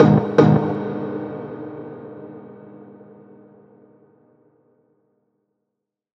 Dark Piano 1.wav